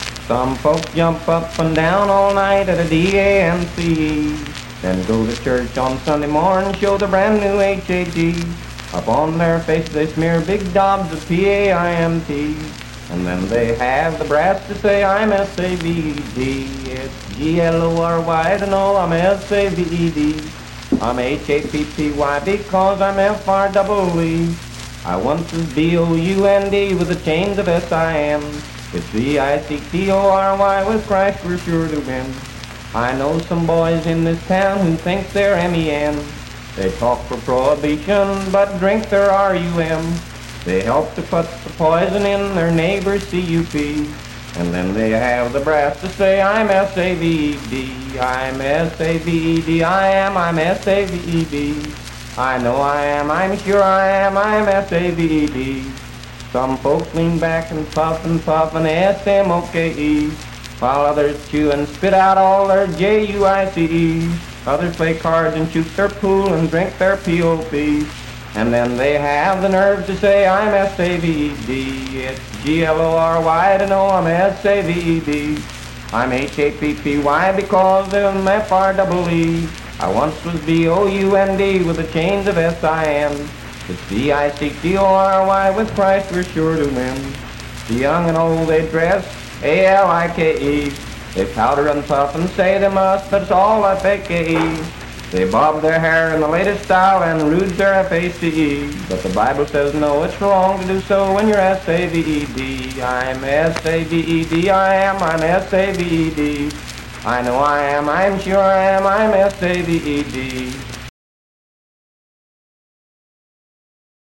Unaccompanied vocal performance
Hymns and Spiritual Music
Voice (sung)
Spencer (W. Va.), Roane County (W. Va.)